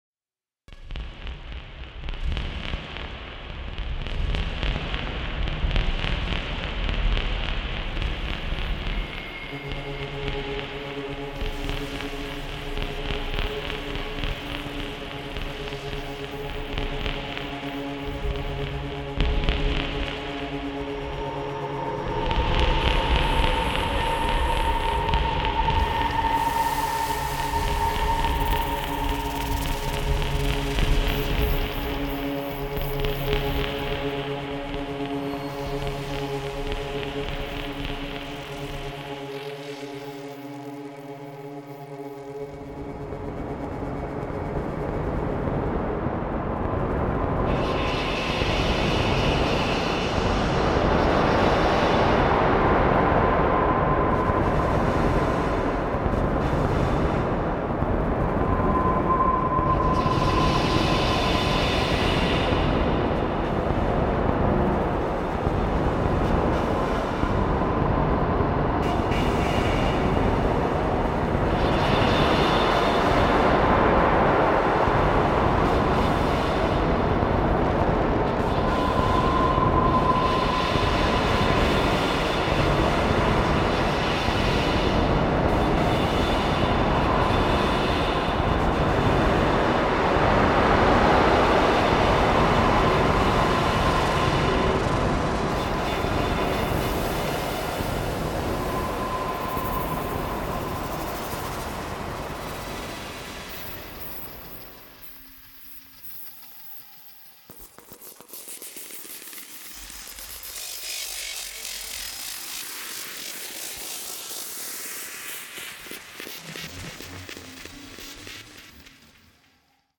Genre:Cinematic
すべてのサンプルは24ビット高品質オーディオで提供され、100％ロイヤリティフリーです。
深いパッド、心に残るドローン、重厚なサウンドスケープに没入し、あらゆるプロジェクトに即座に雰囲気をもたらします。